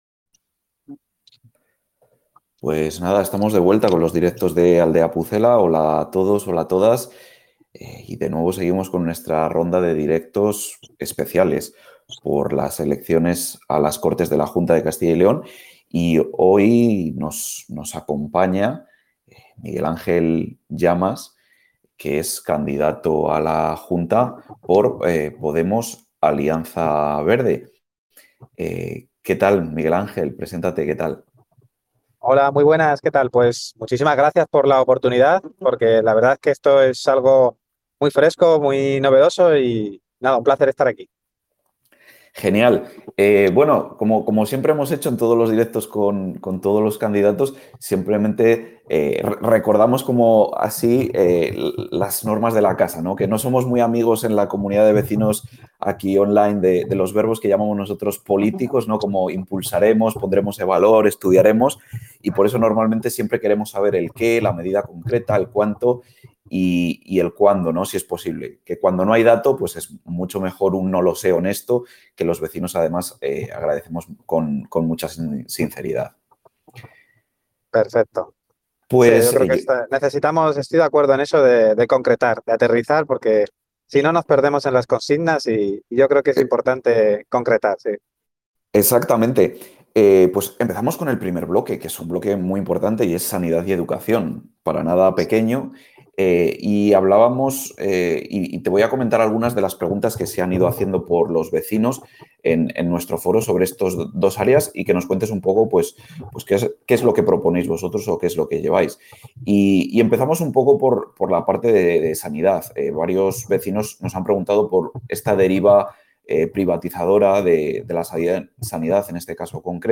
Continuamos nuestros Directos con los candidatos a las elecciones de la Junta 2026.
En esta ocasión, charlamos con Miguel Ángel Llamas, candidato a la Junta por Podemos-Alianza Verde.